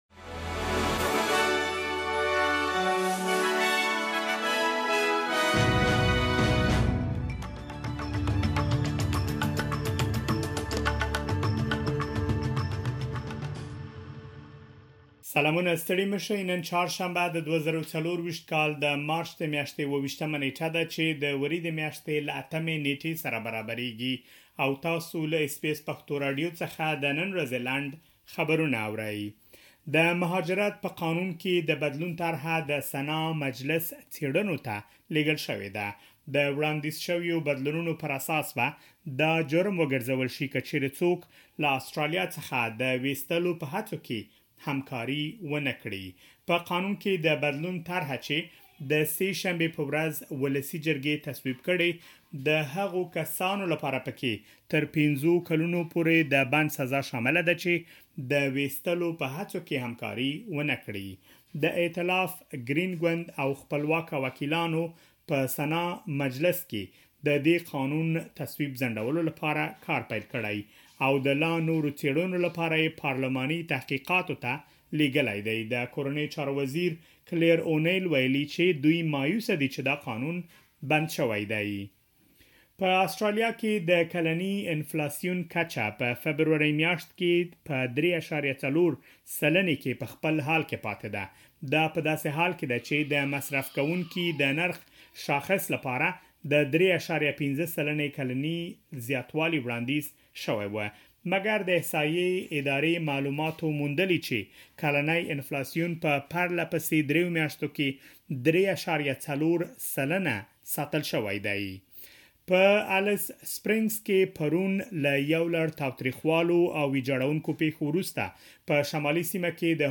د اس بي اس پښتو راډیو د نن ورځې لنډ خبرونه|۲۷ مارچ ۲۰۲۴